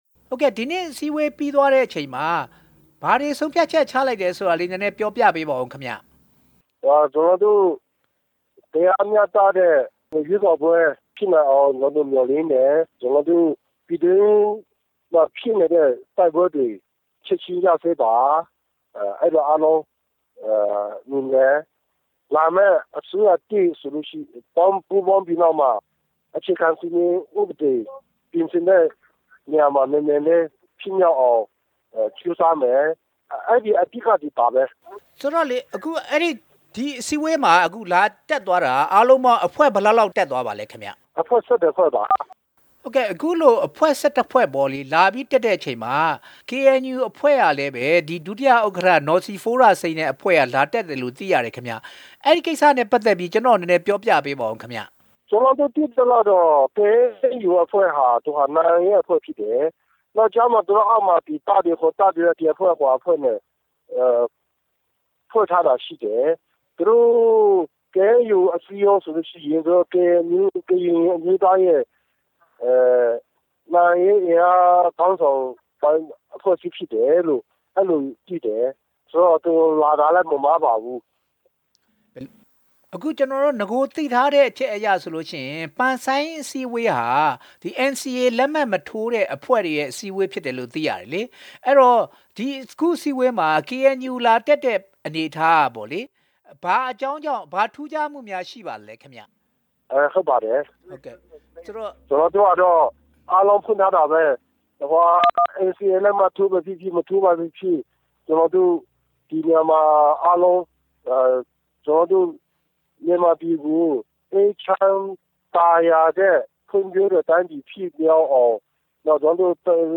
ပန်ဆန်း ညီလာခံအကြောင်း မေးမြန်းချက်